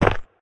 step-4.ogg